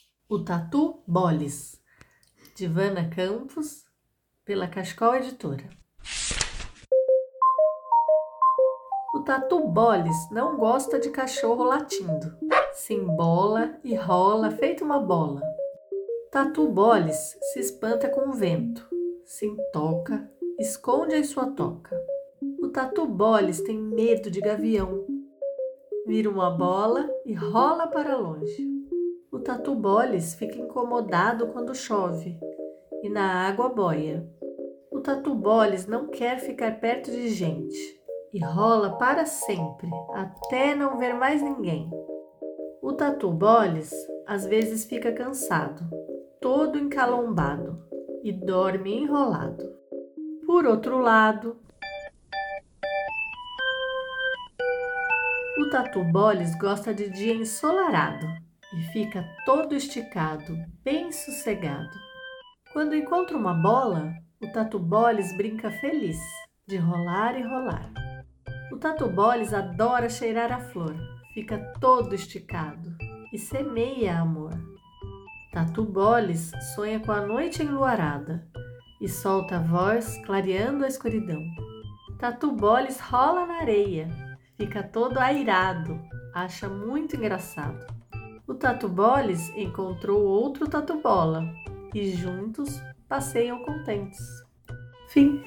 TatuBolis_audiolivro_mixdown.mp3